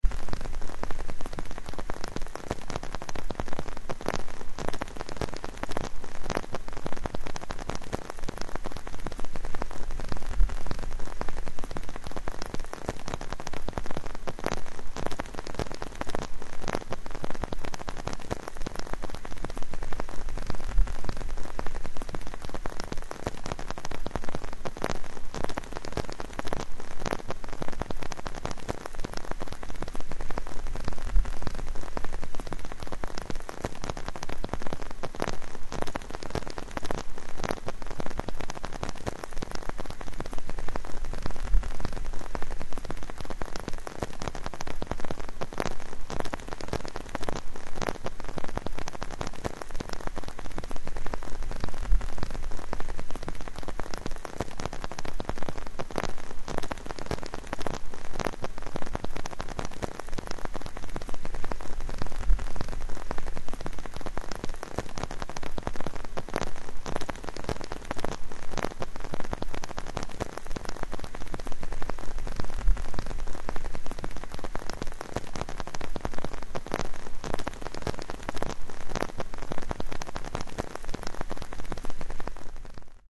Звуки зонтика
Шум дождя, стучащего по зонту